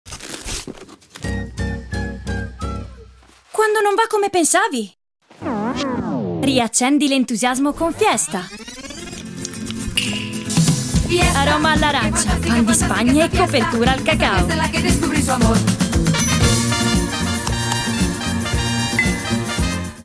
spot web
caratterizzazioni varie da bambini, ragazzini a donne